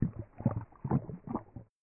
Custom sloshing noise system, allows individual bellies to be enabled to slosh louder and more frequently with how much is in them, no worries, made it so the system just bases chance on the highest volume at a given time and not spam sounds, also the sloshing noises are tied to digestion noises preference for those not wishing to hear that stuff.
walkslosh6.ogg